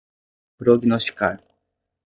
Prononcé comme (IPA)
/pɾo.ɡu.i.nos.t͡ʃiˈka(ʁ)/